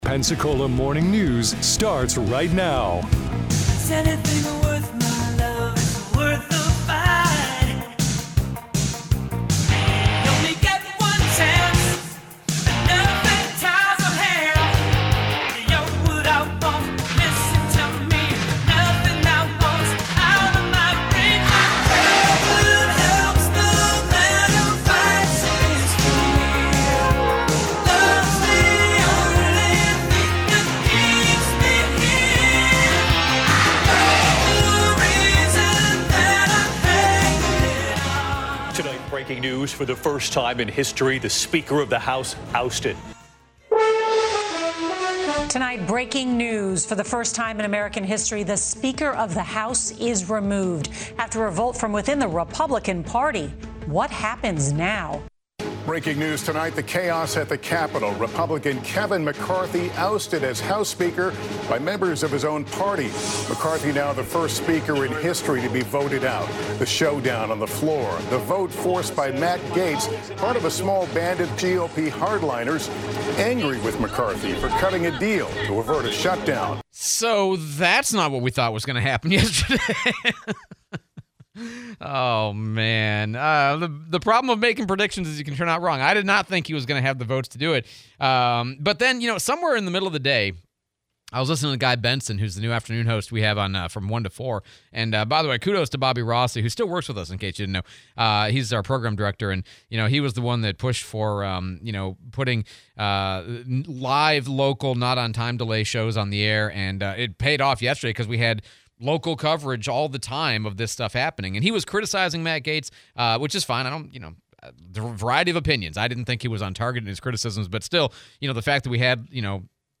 Kevin McCarthy ousted as Speaker of the House; DC Reeves, mayor of Pensacola, joins the show to discuss recycling, says they are renting trucks and double staffing to pick up recycle cans; Police sub station near PSC/more feet on the street; planting trees/tree planting trust fund/forestry grant